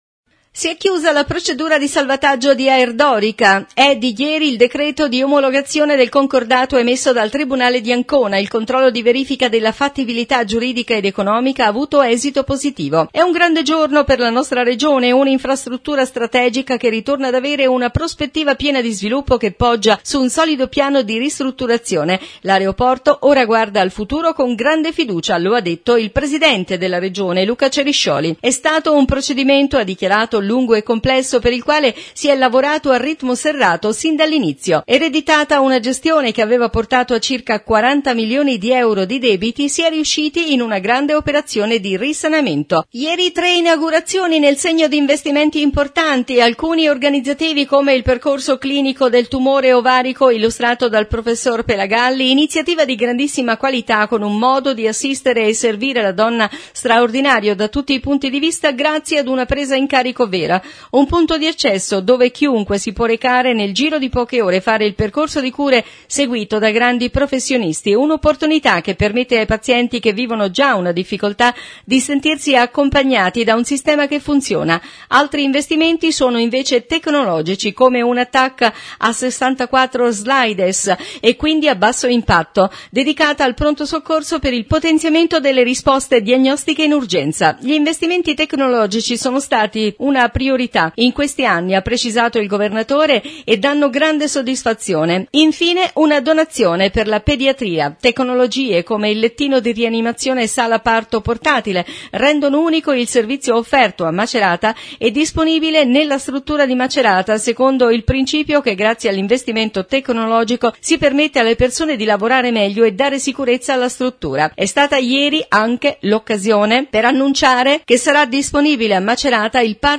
New Radio Star | Notizie Regione … 3 inaugurazioni all’ospedale di Macerata Intervista Luca Ceriscioli – Presidente Regione Marche